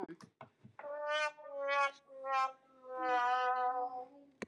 Whomp Whomp Efecto de Sonido Descargar
Whomp Whomp Botón de Sonido